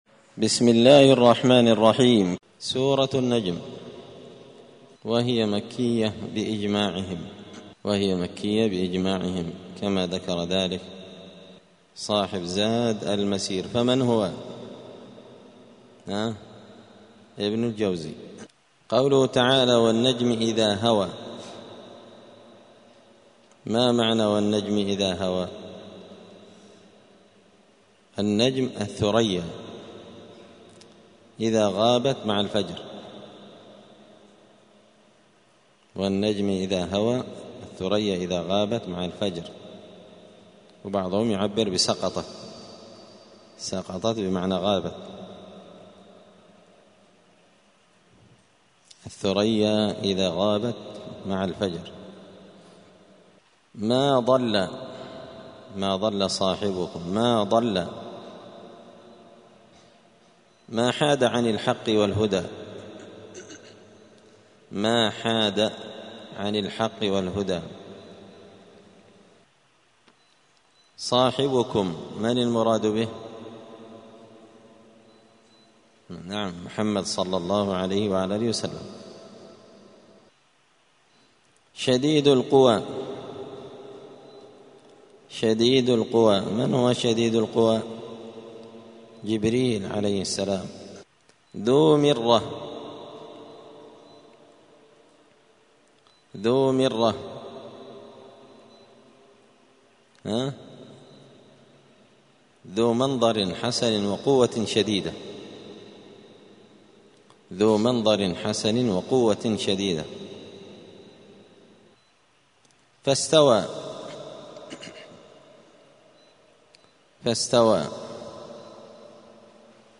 الأحد 18 ذو القعدة 1445 هــــ | الدروس، دروس القران وعلومة، زبدة الأقوال في غريب كلام المتعال | شارك بتعليقك | 26 المشاهدات